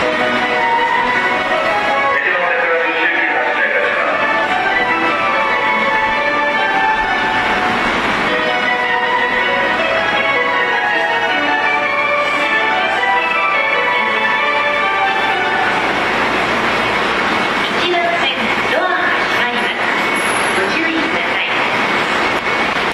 発車1音色a 曲は普通です。